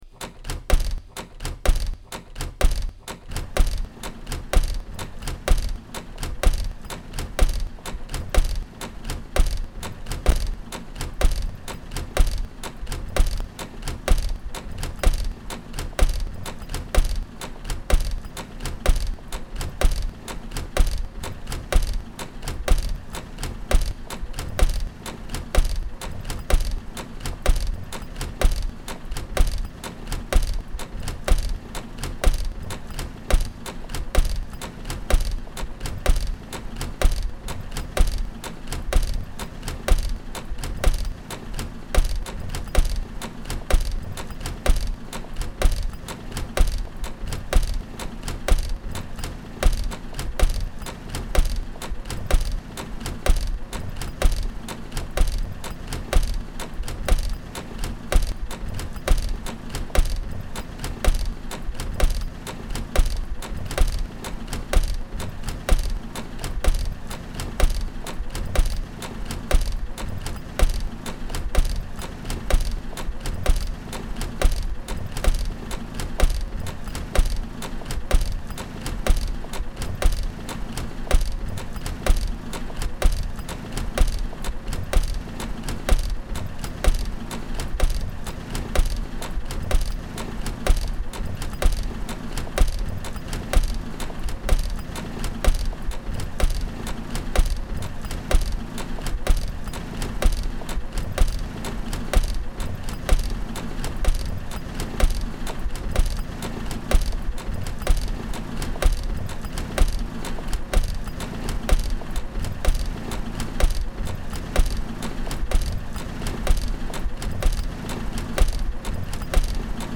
Leur présence s'est accompagnée de l'enregistrement in situ du son.